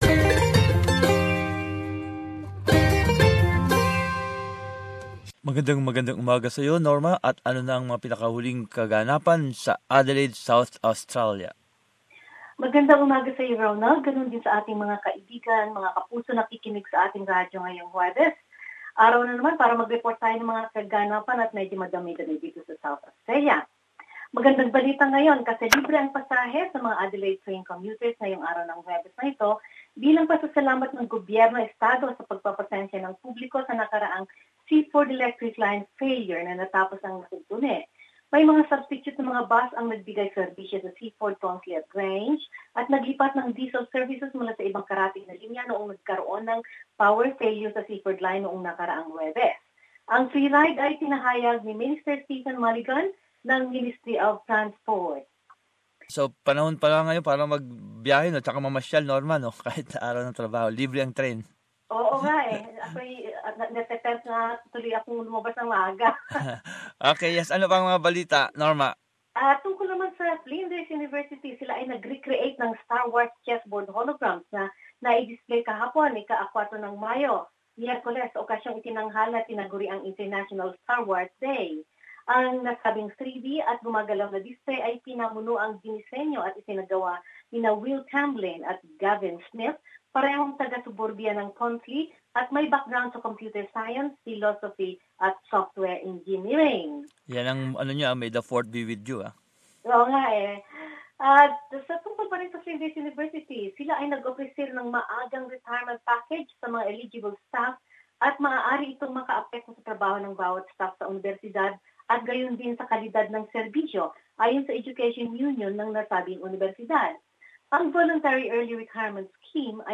Adelaide News.